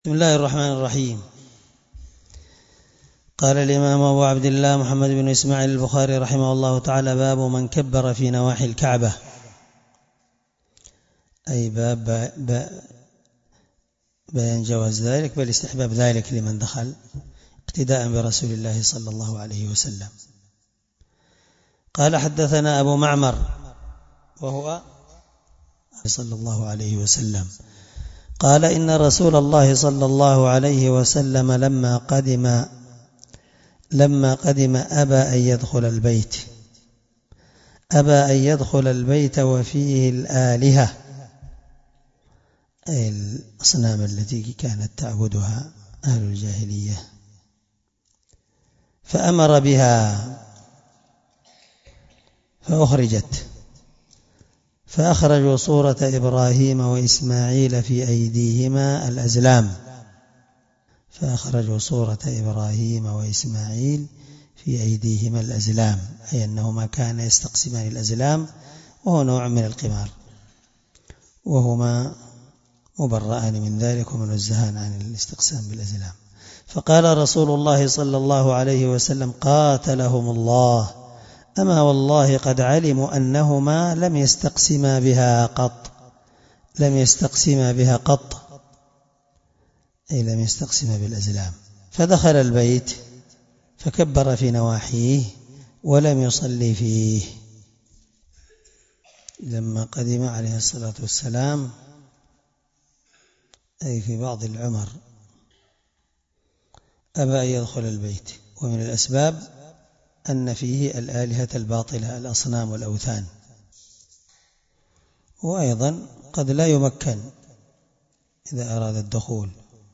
الدرس38 من شرح كتاب الحج حديث رقم(1601 )من صحيح البخاري